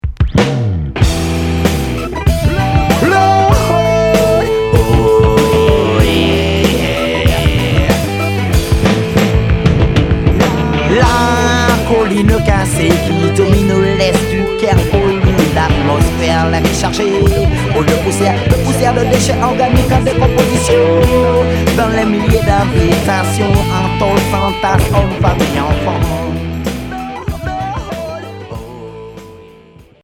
Reggae rock fusion Cinquième 45t